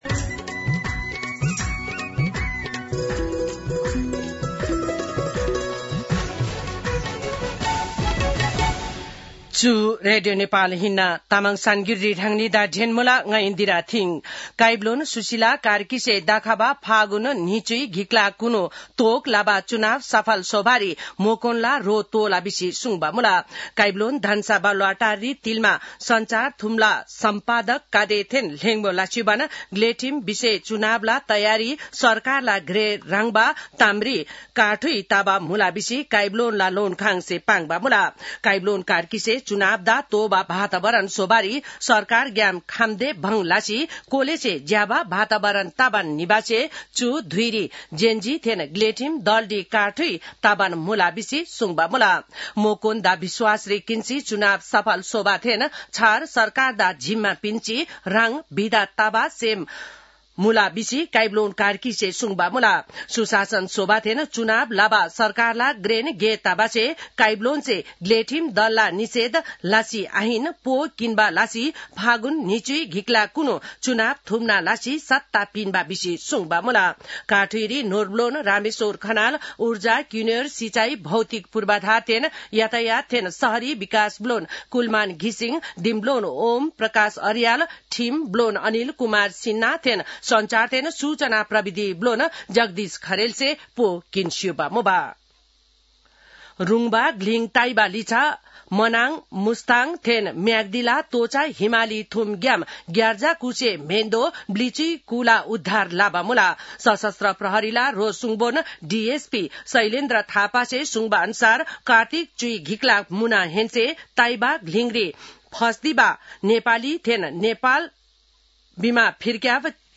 तामाङ भाषाको समाचार : १५ कार्तिक , २०८२